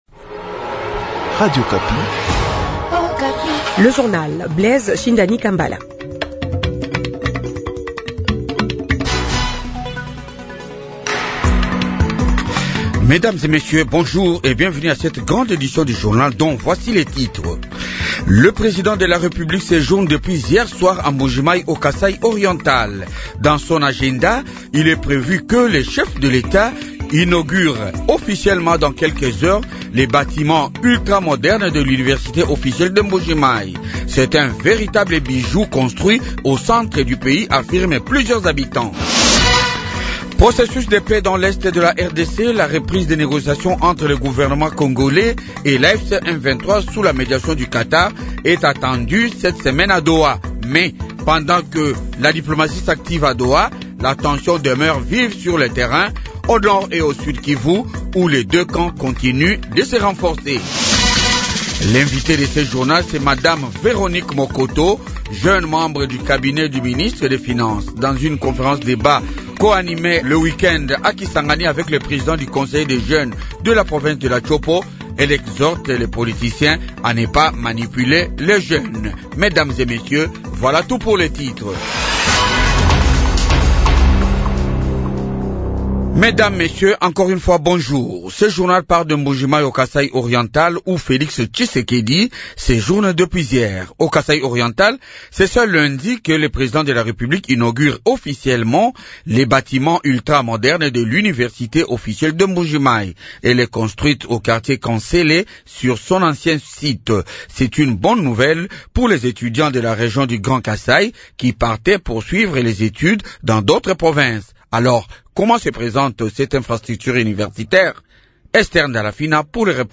-Mbujimayi : Vox pop de la population du Kasaï oriental